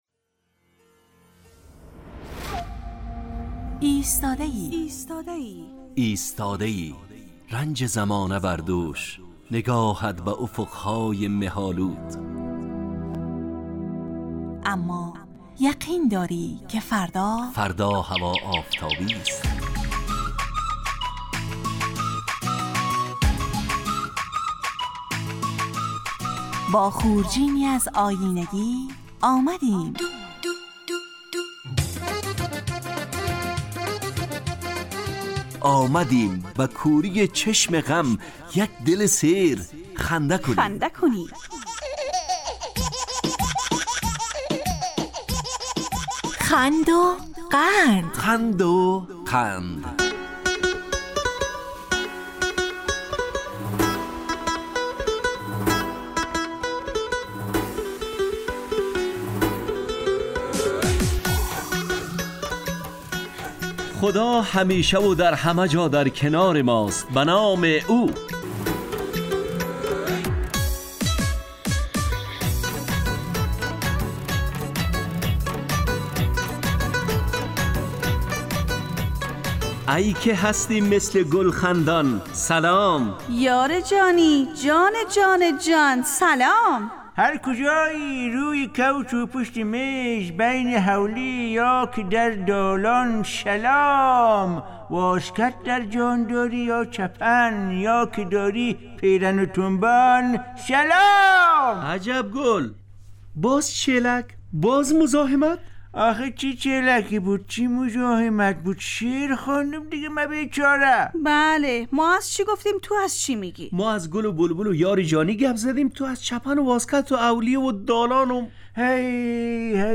خند وقند برنامه ای طنز در قالب ترکیبی نمایشی است که هرجمعه به مدت 35 دقیقه در ساعت 9:15 به وقت ایران و 10:15 به وقت افغانستان از رادیو دری پخش میگردد.